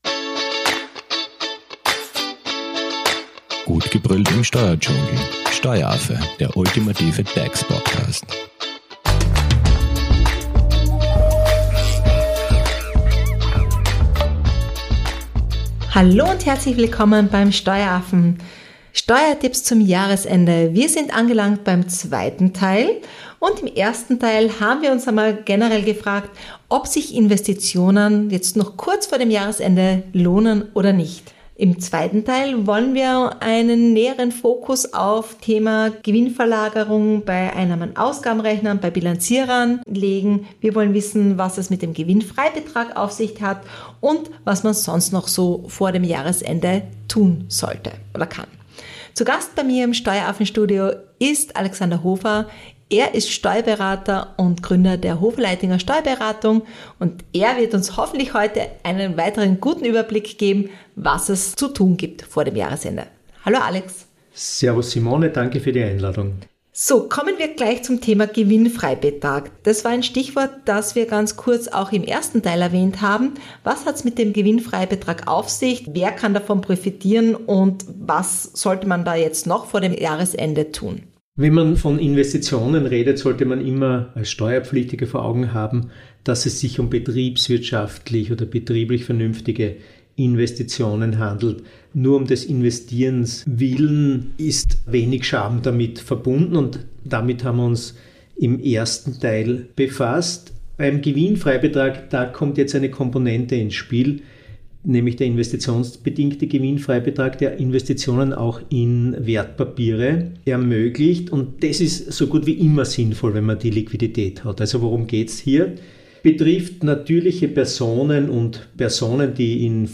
Weiters beantworten wir Fragen zur vorteilhaften Gewinnverlagerung bei Einnahmen-Ausgaben-Rechnern und Bilanzierern. Zu Gast im Steueraffen-Studio